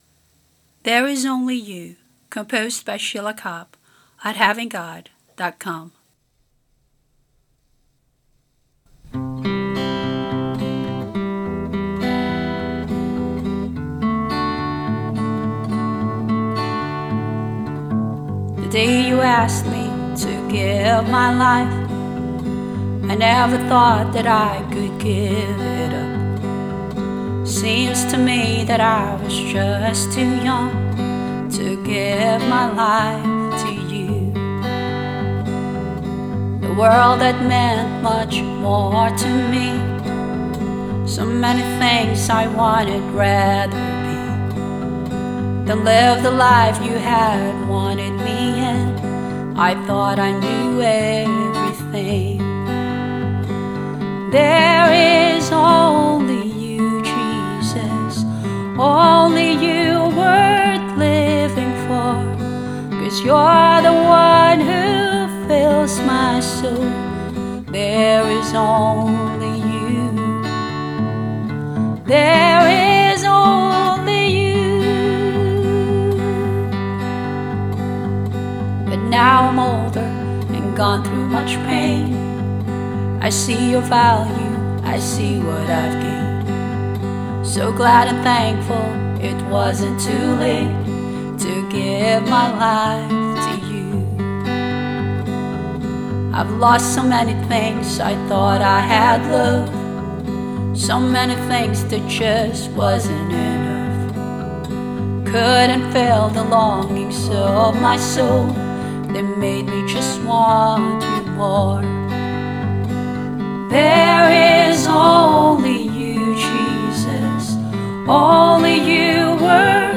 Voices and guitar